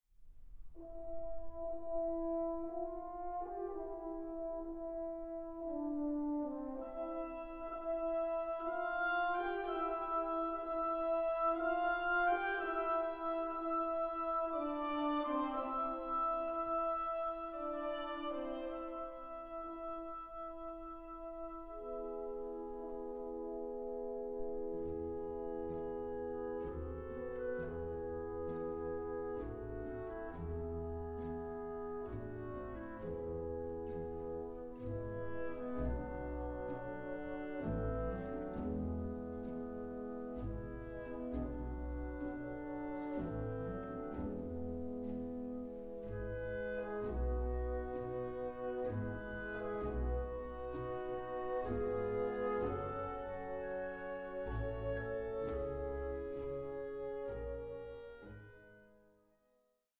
Andante moderato 11:11